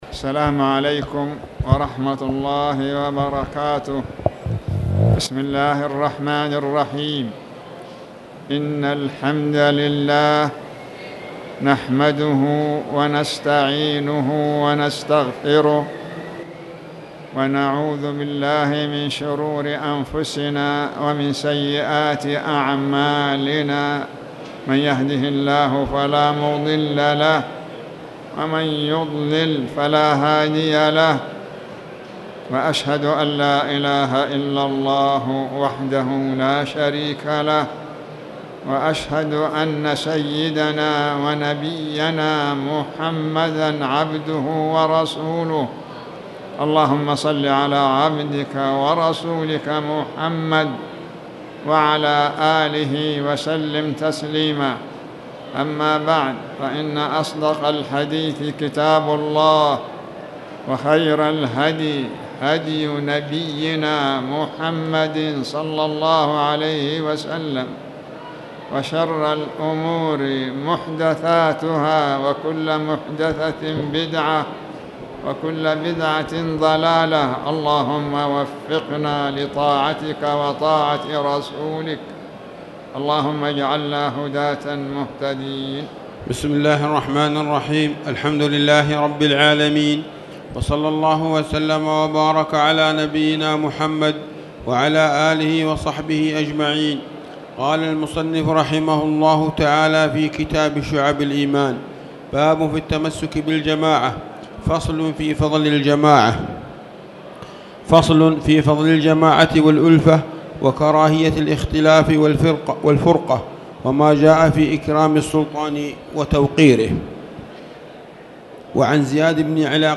تاريخ النشر ١٩ ربيع الأول ١٤٣٨ هـ المكان: المسجد الحرام الشيخ